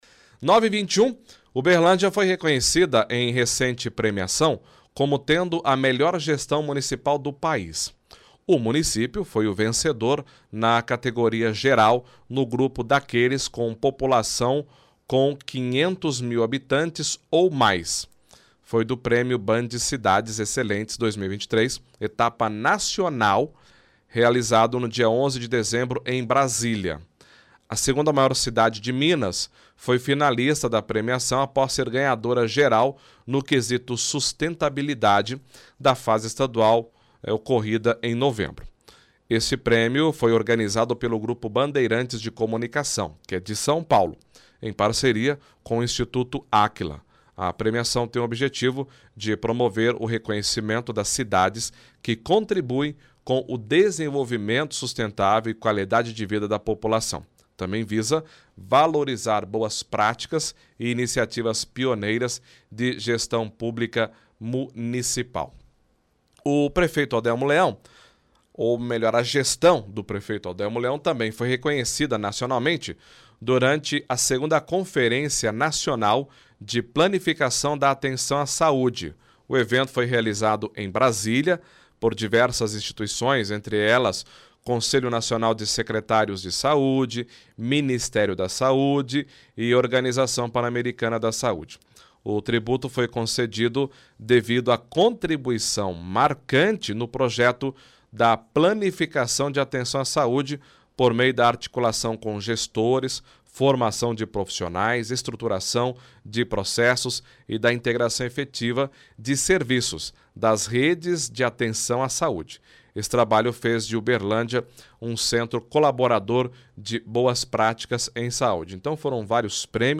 Entrevista prefeito Odelmo em evento ontem à tarde, faz agradecimento, diz que é um prêmio onde todos estão envolvidos.
Leitura de release